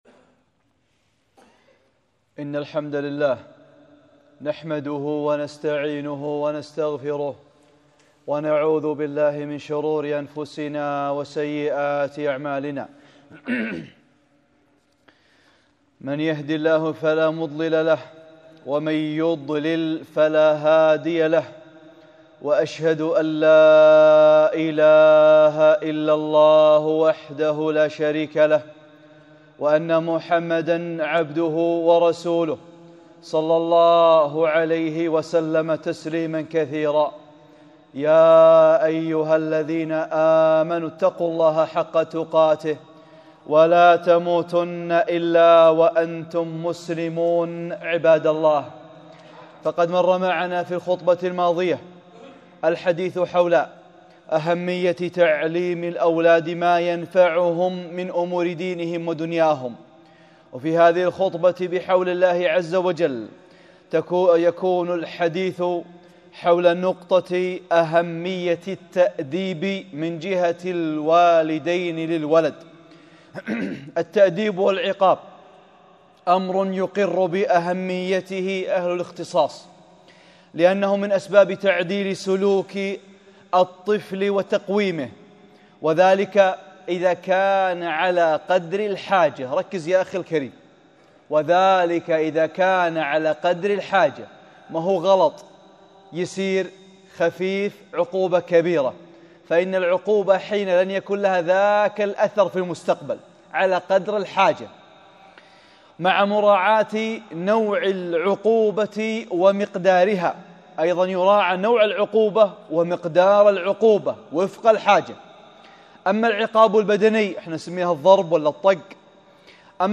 (25) خطبة - التأديب | أمور هامة متعلقة بالآباء والأمهات